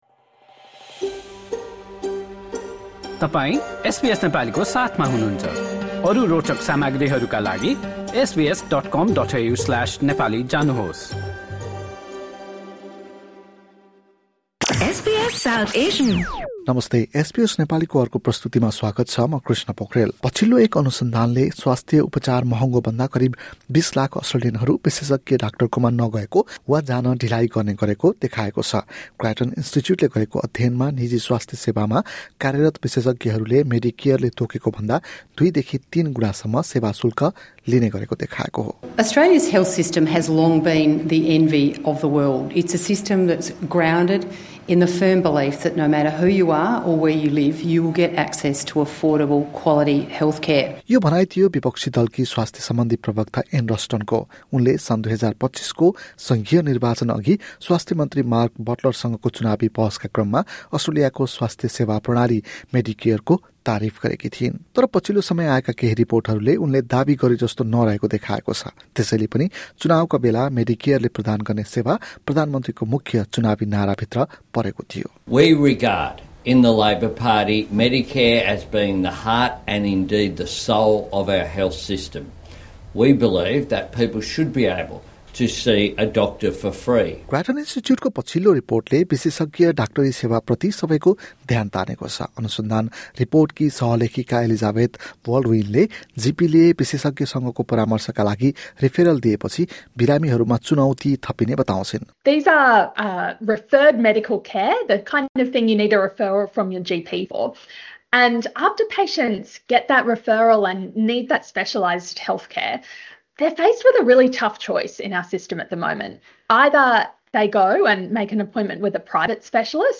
पछिल्लो एक अनुसन्धानले स्वास्थ्य उपचार महँगो बन्दा करिब २० लाख अस्ट्रेलियनहरू विशेषज्ञ डाक्टर कहाँ नगएको वा जान ढिलो गर्ने गरेको देखाएको छ। द ग्राटन इन्स्टिच्युटले गरेको अध्ययनमा निजी स्वास्थ्य सेवामा कार्यरत विशेषज्ञहरूले मेडिकेयरले तोकेको भन्दा दुईदेखि तीन गुणासम्म सेवा शुल्क लिने गरेको देखाएको छ। एक रिपोर्ट।